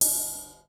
• Cymbal Crash Sound C Key 11.wav
Royality free crash sample tuned to the C note.
cymbal-crash-sound-c-key-11-i59.wav